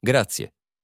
The "ZI" in "grazie" sounds like "TSEE"
So "grazie" is pronounced "GRAHT-see-eh" (not "grah-zee").